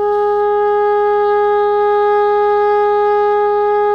SOP  PP G#3.wav